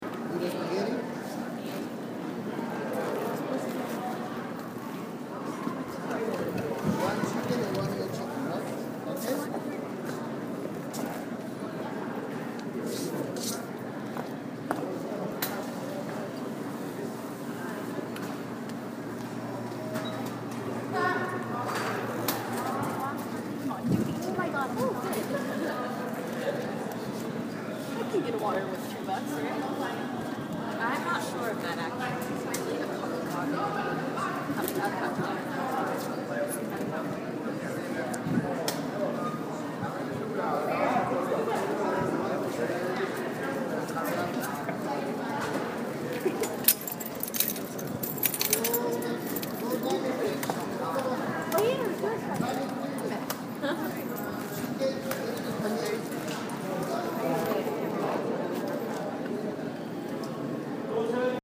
Field Recording #1
Location: In like at CPK in the student center
Sounds: People talking, foot steps, fountain drinks being poured, food being prepared, lids being put on plastic cups, keys jingling.